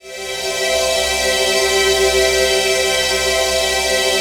PHASEPAD08-L.wav